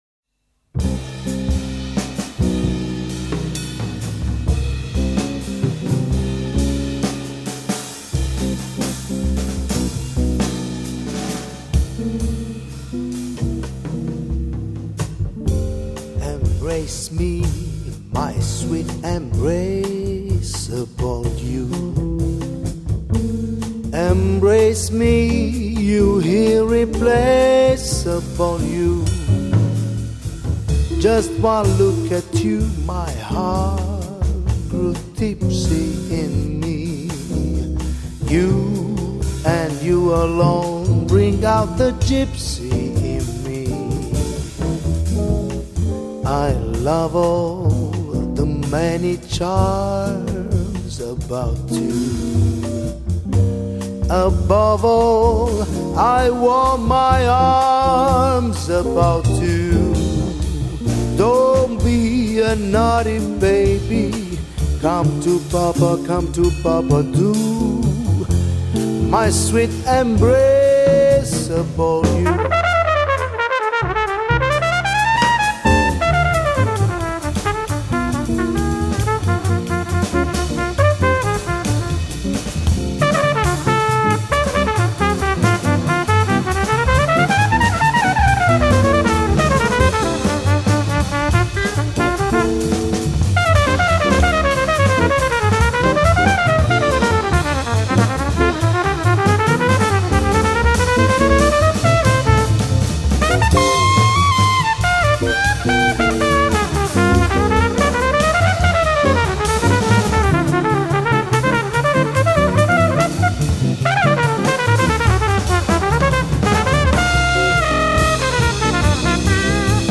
类别： 爵士
主奏乐器：钢琴
一群热爱爵士的乐手以不同的爵士乐风，烘托城市的美妙炫丽，用另一种角度观看城市风情~~~